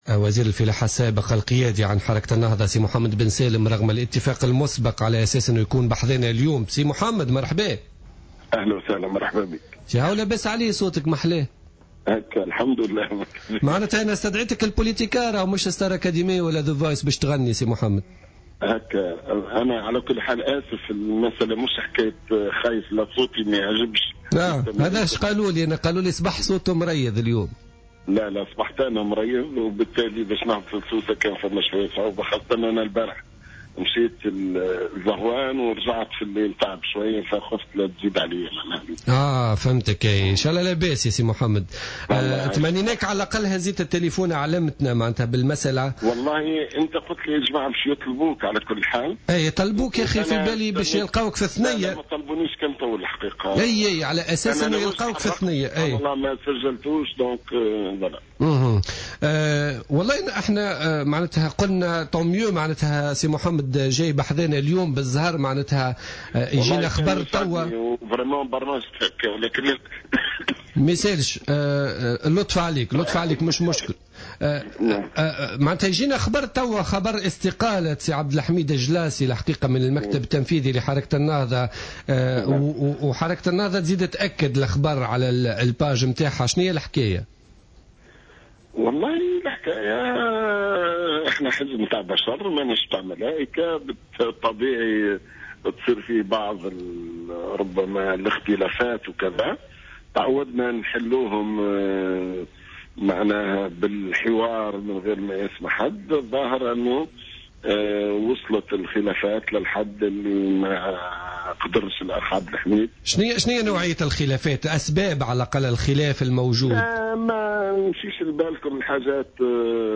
قال القيادي في حركة النهضة،محمد بن سالم في مداخلة له في برنامج "بوليتيكا" إن عبد الحميد الجلاصي لم يستقل من الحركة وإنما من مهامه في المكتب التنفيذي.